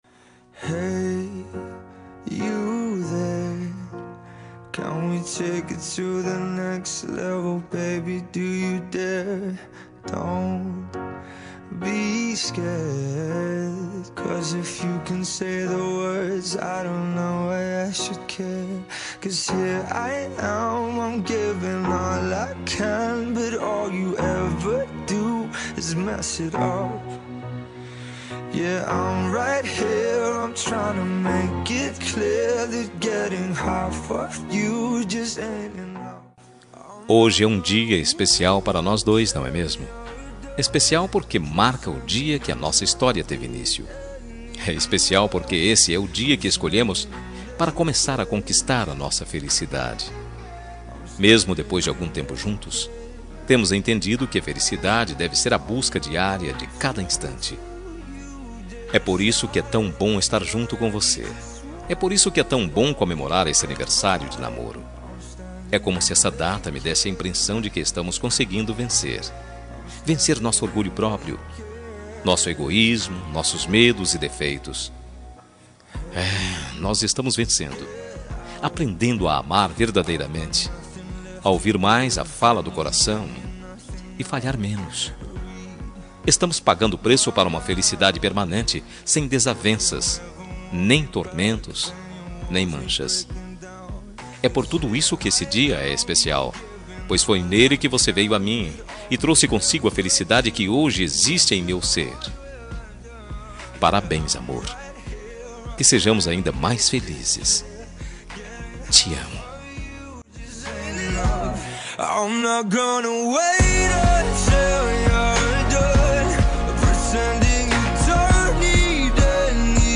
Telemensagem Aniversário de Namoro – Voz Masculina – Cód: 8102- Linda.